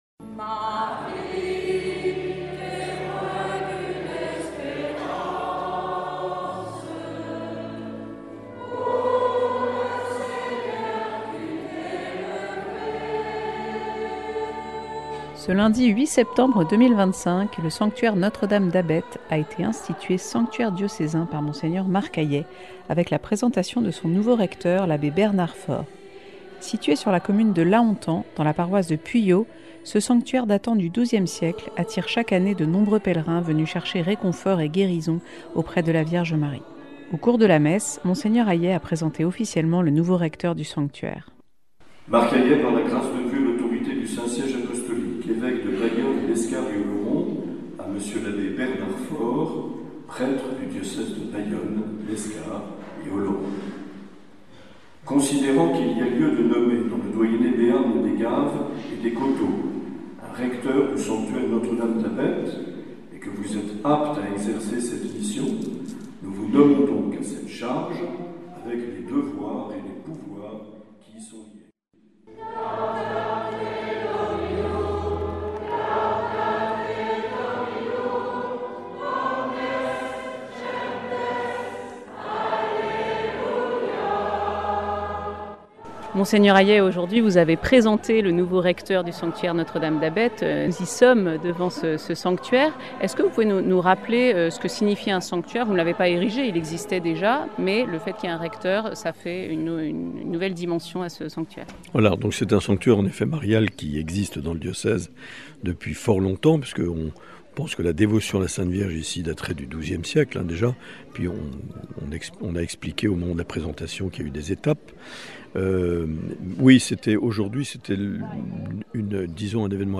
Avec des témoignages de guérison.